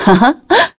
Scarlet-vox-Happy4.wav